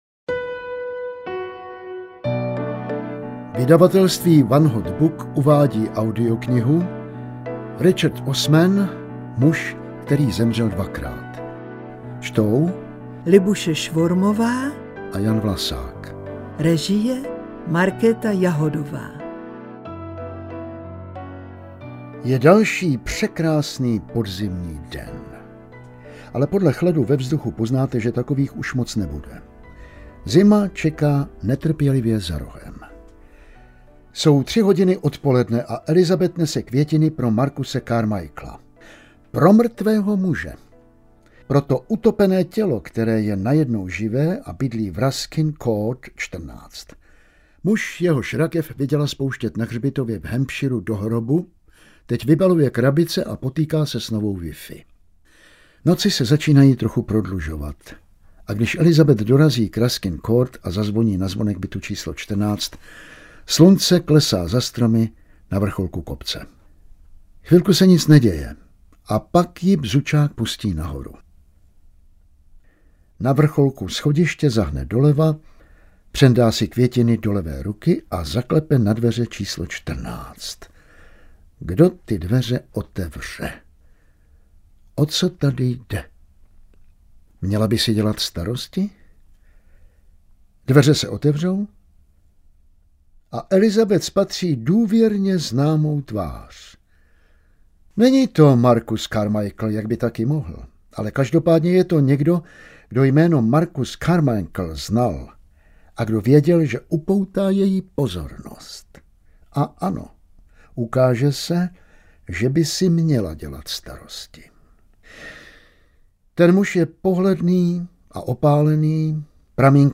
Muž, který zemřel dvakrát audiokniha
Ukázka z knihy
• InterpretLibuše Švormová, Jan Vlasák